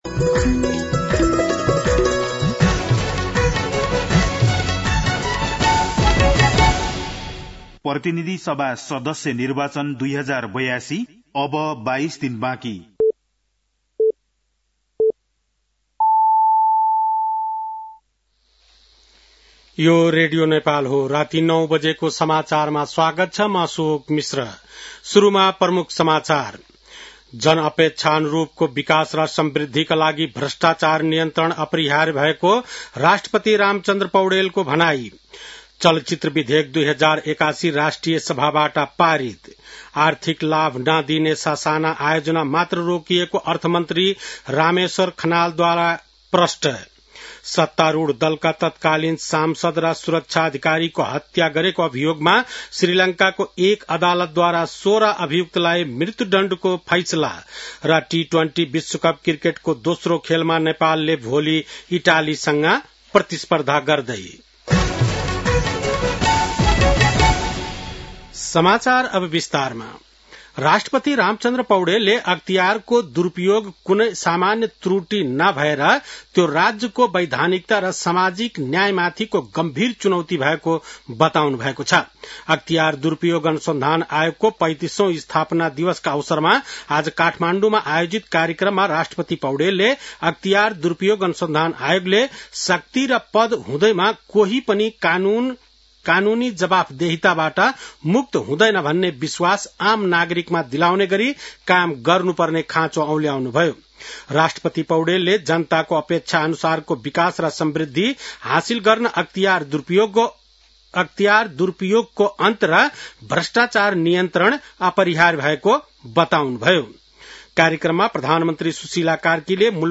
बेलुकी ९ बजेको नेपाली समाचार : २८ माघ , २०८२
9-pm-nepali-news-10-28.mp3